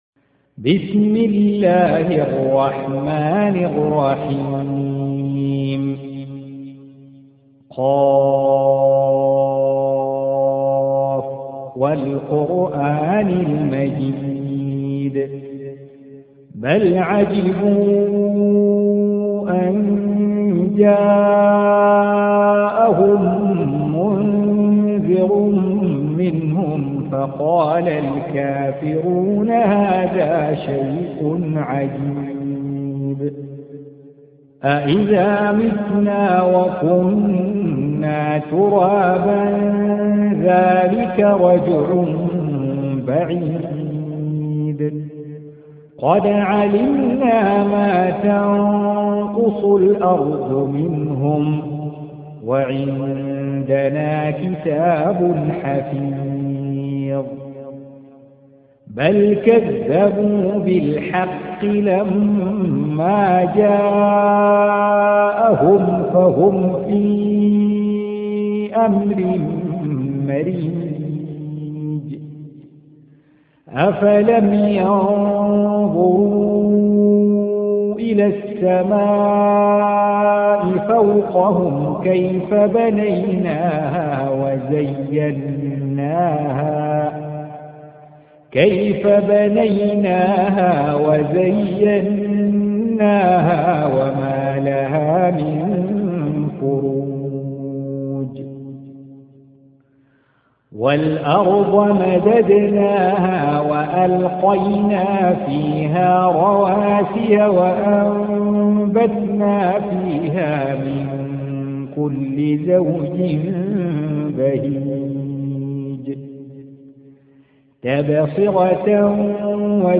Surah Sequence تتابع السورة Download Surah حمّل السورة Reciting Murattalah Audio for 50. Surah Q�f. سورة ق N.B *Surah Includes Al-Basmalah Reciters Sequents تتابع التلاوات Reciters Repeats تكرار التلاوات